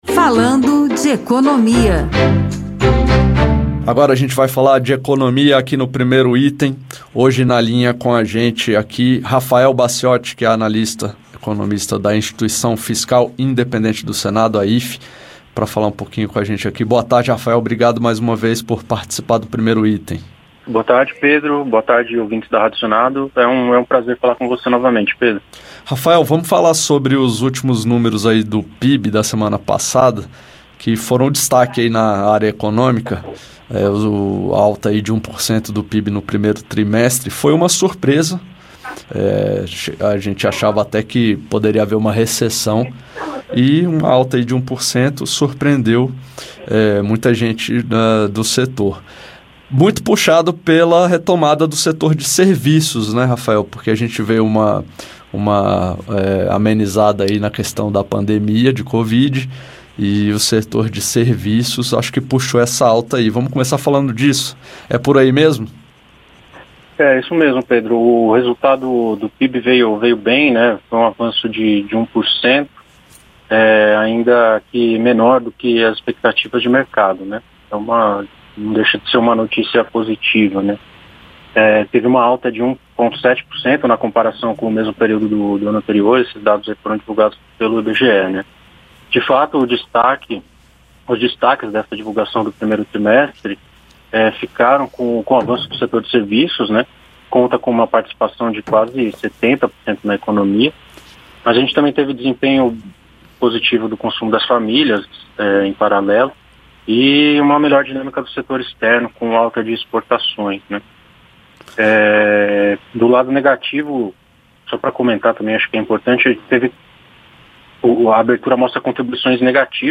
Os últimos números foram afetados pela retomada no setor de serviços pós pandemia, mas o crescimento da indústria segue baixo. Acompanha a entrevista.